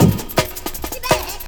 Think2 Breakbeat 32k